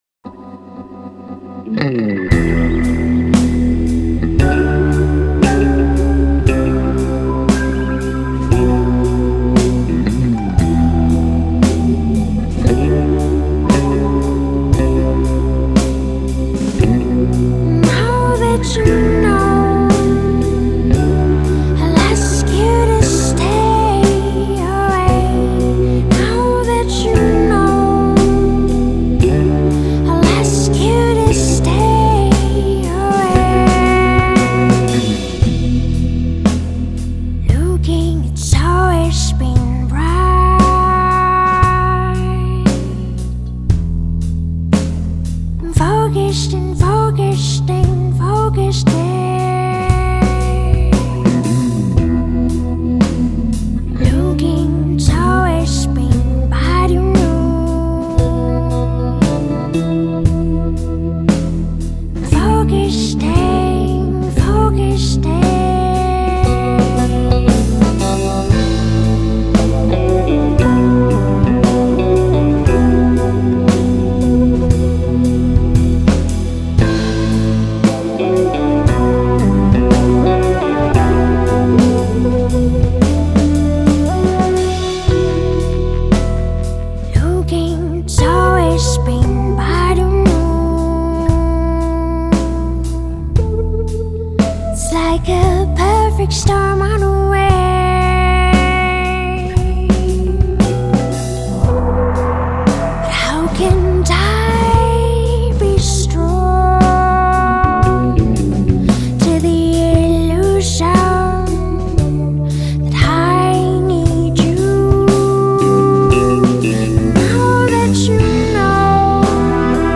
the Canadian duo